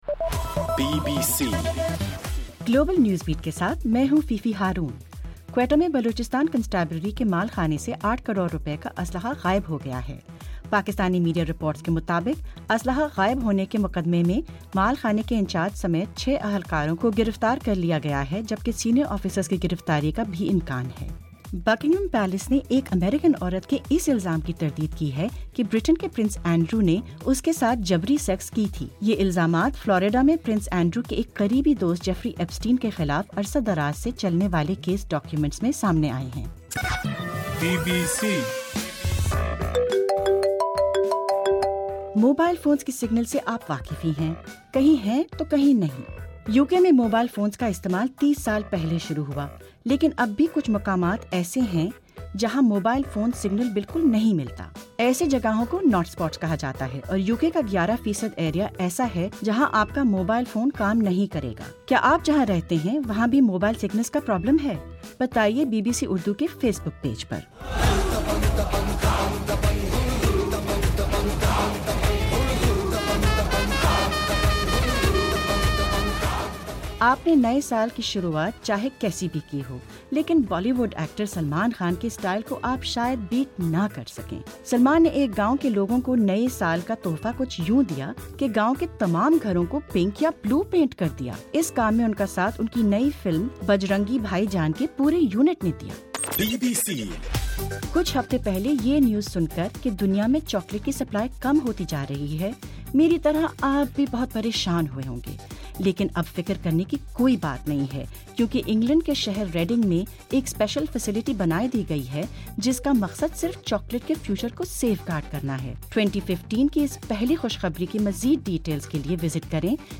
جنوری 02: رات 11 بجے کا گلوبل نیوز بیٹ بُلیٹن